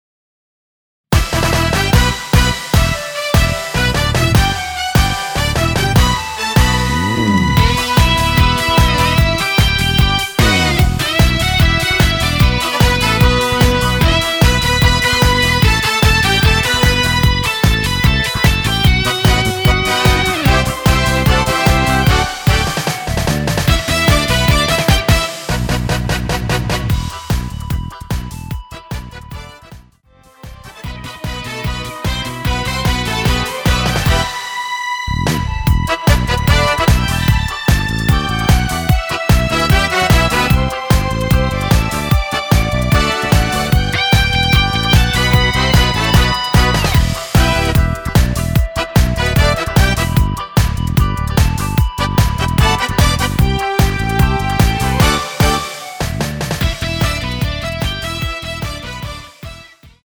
원키에서(+5)올린 멜로디 포함된 MR입니다.(미리듣기 참조)
Em
앞부분30초, 뒷부분30초씩 편집해서 올려 드리고 있습니다.
중간에 음이 끈어지고 다시 나오는 이유는